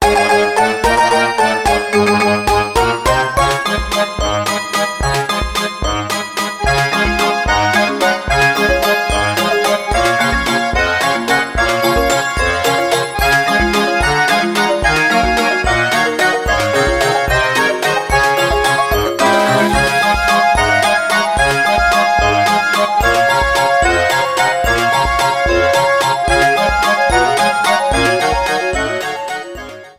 Ripped from the ISO
Faded in the end
Fair use music sample